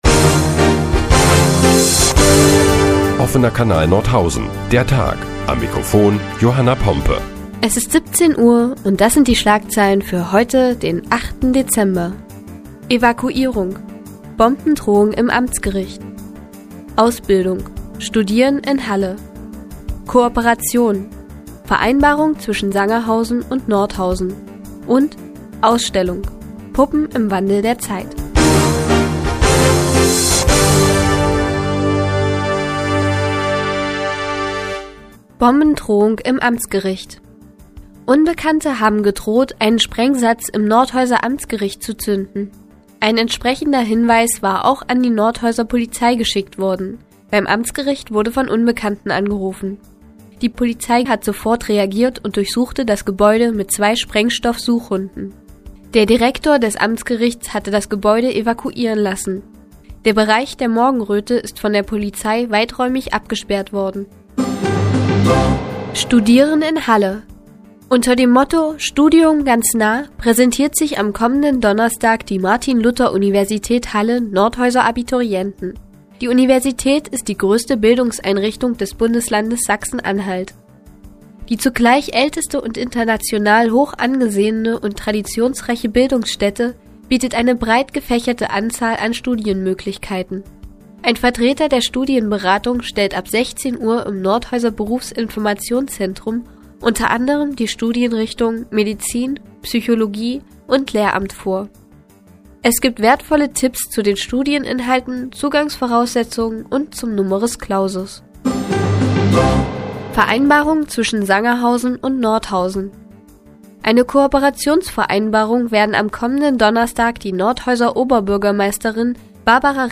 Die tägliche Nachrichtensendung des OKN ist nun auch in der nnz zu hören. Heute geht es unter anderem um eine Bombendrohung im Nordhäuser Amtsgericht und Kooperationsvereinbarungen zwischen Sangerhausen und Nordhausen.